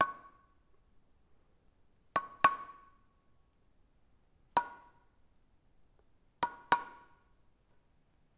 Schlagbrett aus Holz, das mit einem Holzhammer angeschlagen wird.